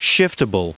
Prononciation du mot shiftable en anglais (fichier audio)
Prononciation du mot : shiftable